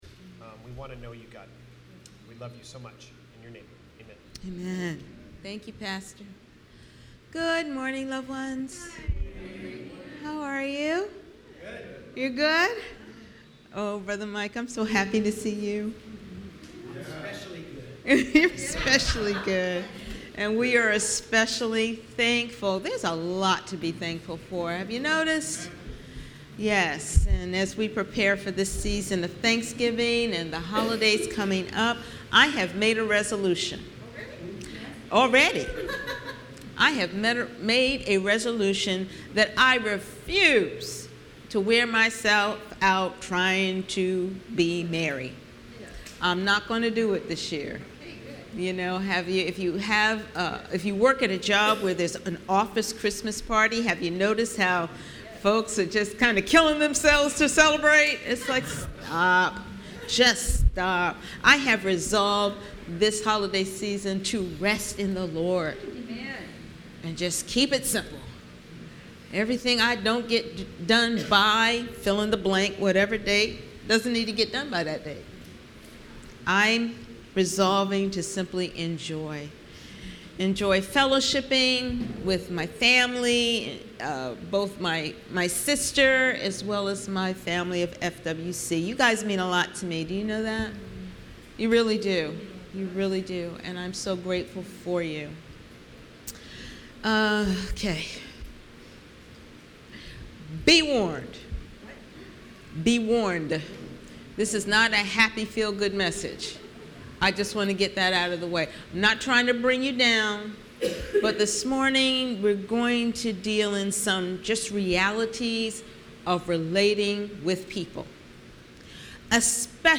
A Gift To All Passage: Joshua 22:1-34 Service Type: Sunday Morning %todo_render% Related « Always Faithful Advent